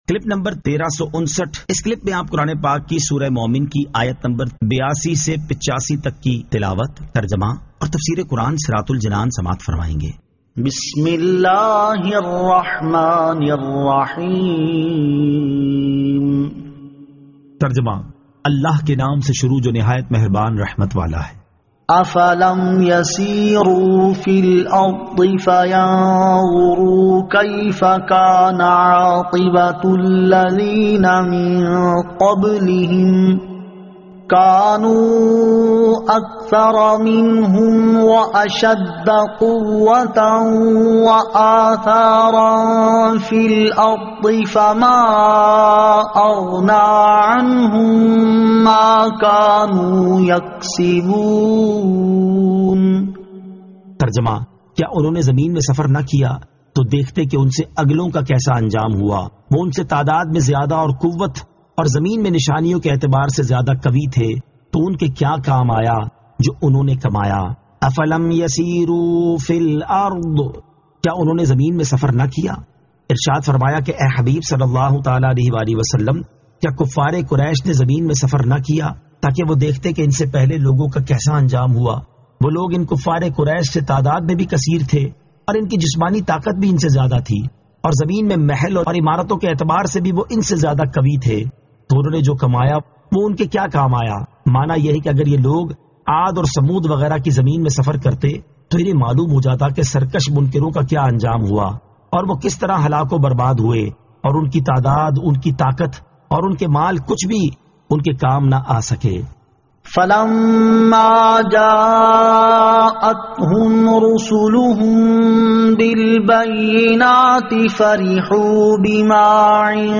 Surah Al-Mu'min 82 To 85 Tilawat , Tarjama , Tafseer